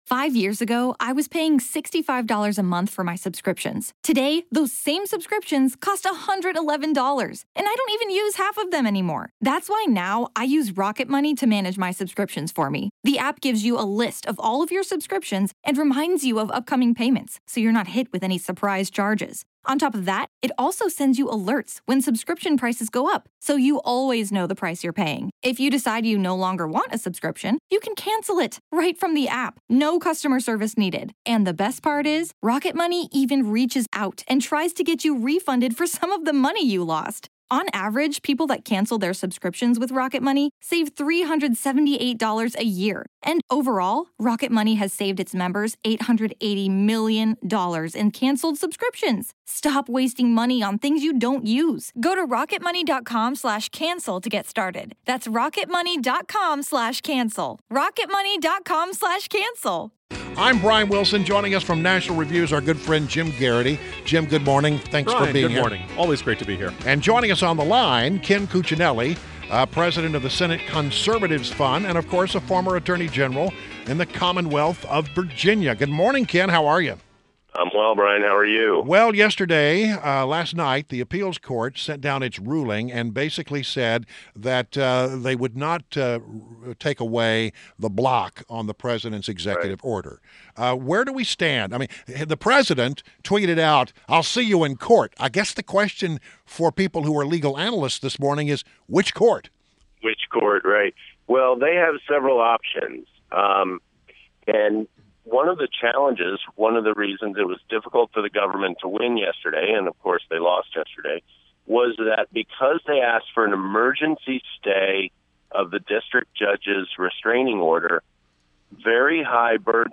INTERVIEW – KEN CUCCINELLI – president of Senate Conservatives Fund and the former Attorney General of Virginia